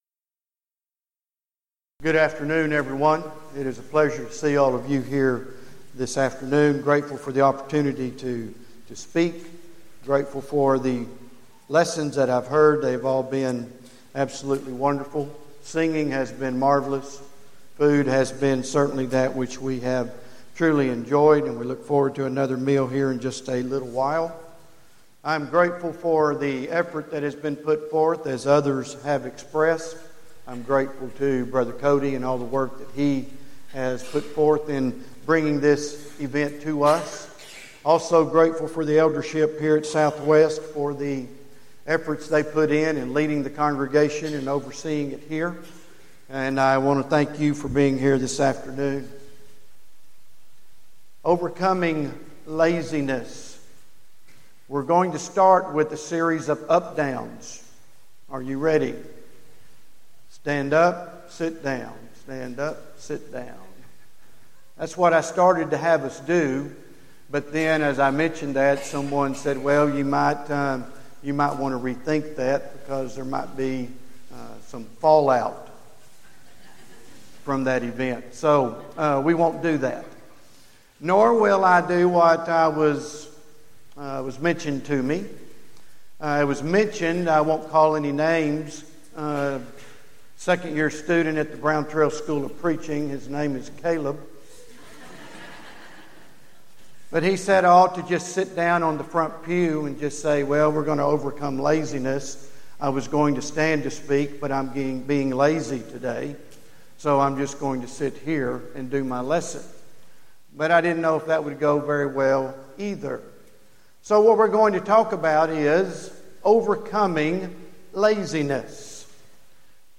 Event: 6th Annual Southwest Spiritual Growth Workshop
lecture